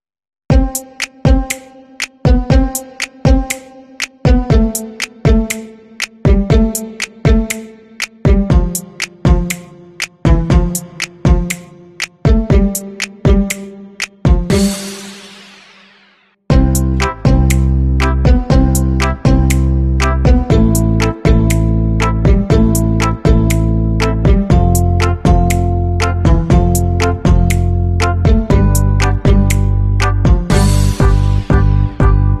Mama cat takecare of her sound effects free download